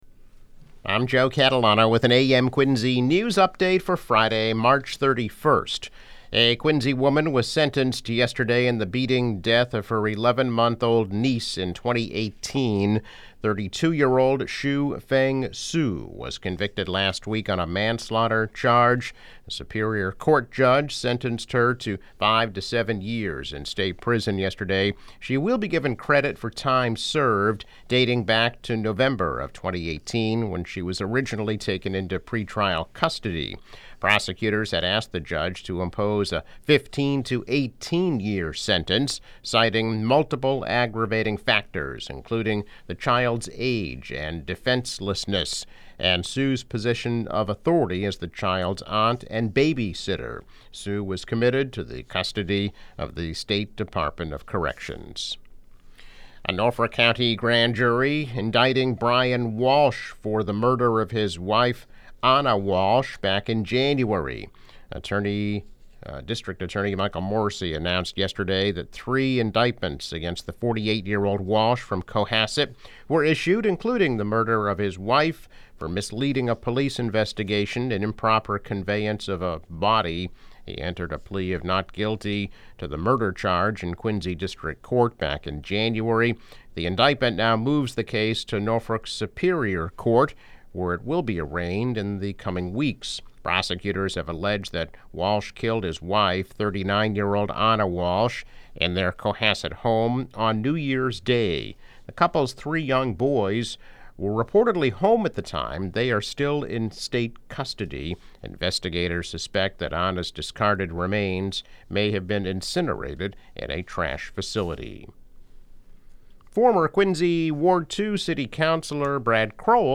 Woman sentenced for death of niece. Former councilor undergoes surgery. Councilor running again.  Daily news, weather and sports update.